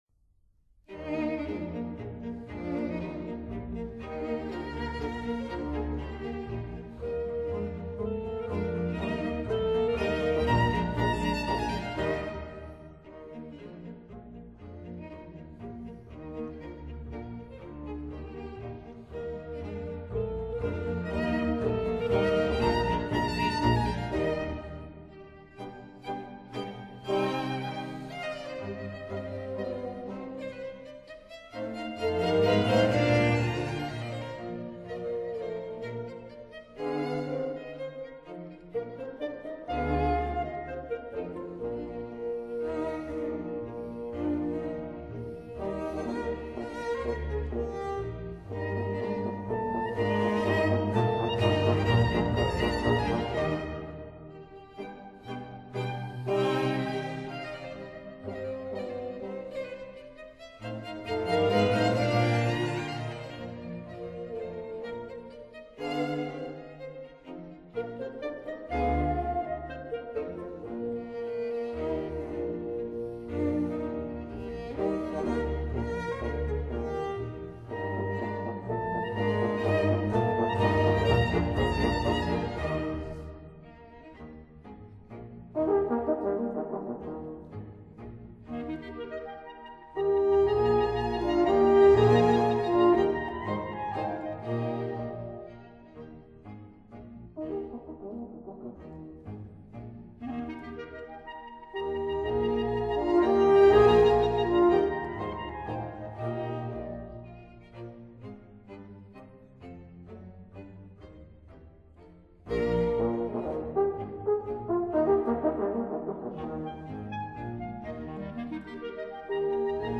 Tempo di minuetto    [0:03:11.31]